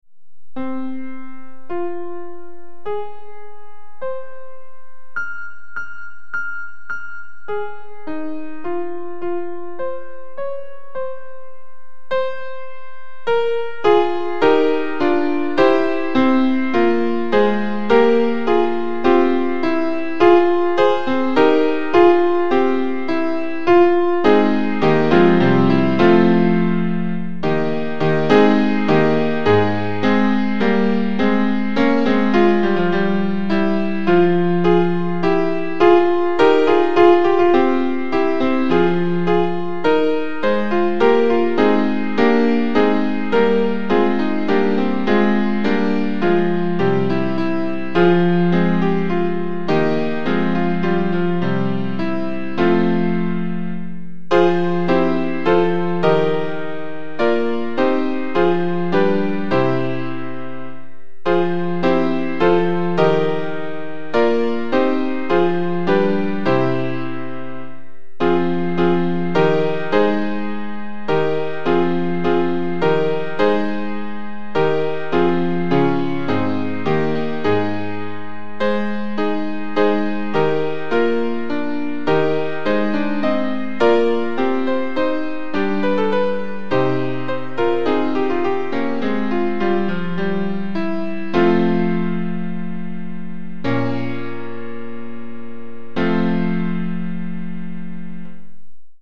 Freshmen Honor Choir
All voices use this file (original file)